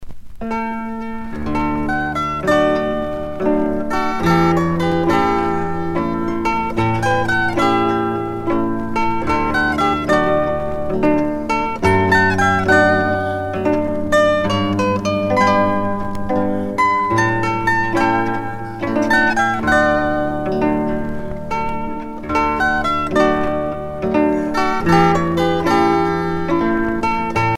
danse : valse lente